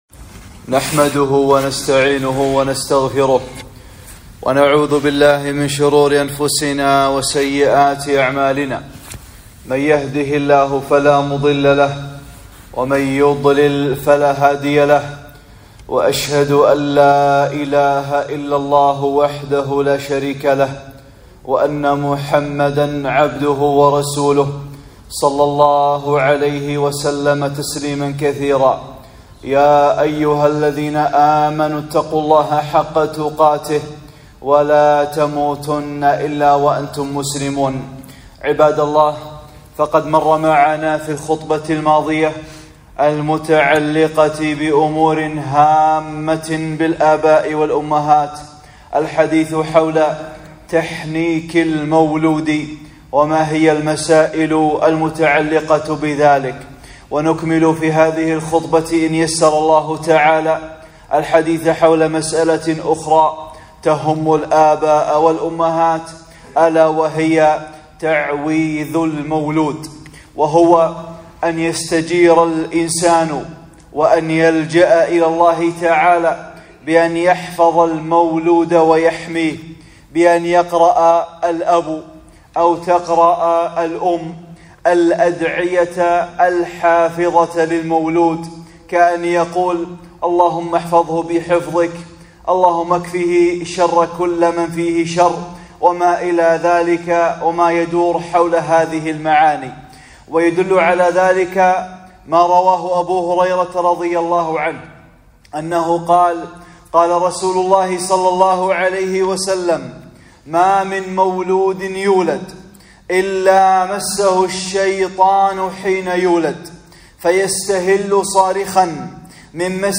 (14) خطبة - تعويذ المولولد - أمور هامة متعلقة بالآباء والأمهات